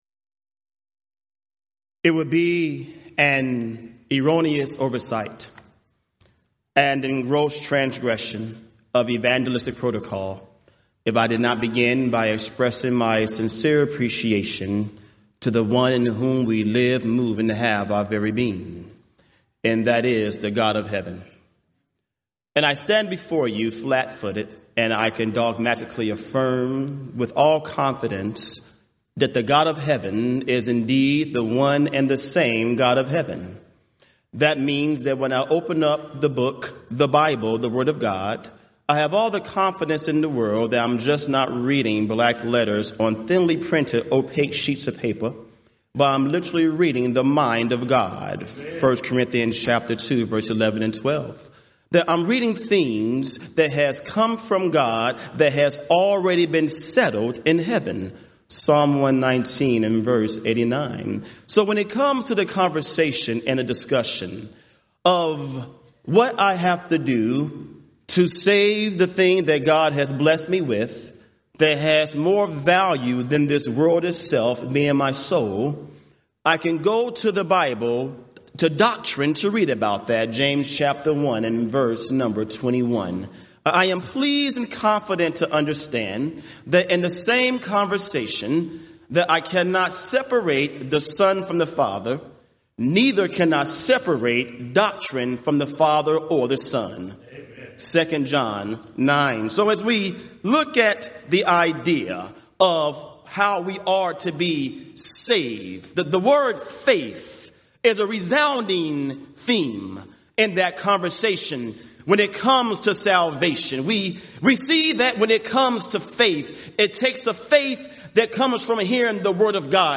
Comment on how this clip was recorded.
Event: 34th Annual Southwest Lectures